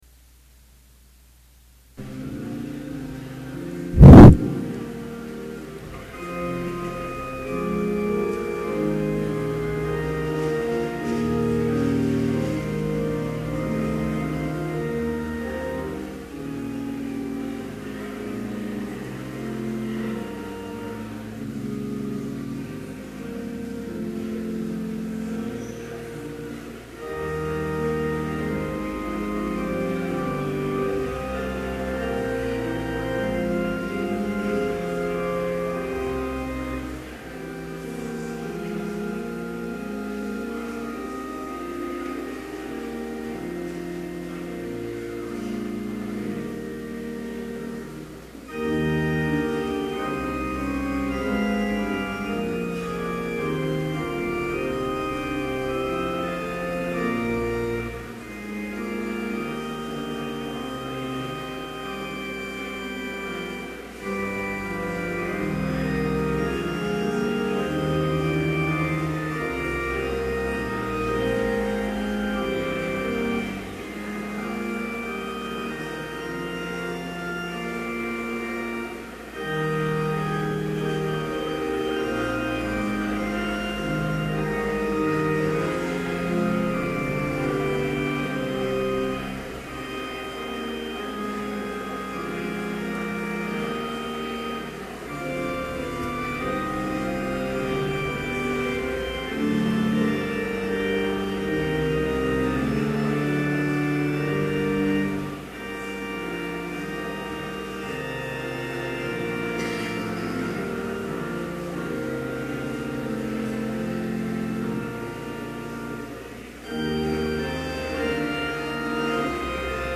Complete service audio for Chapel - August 30, 2011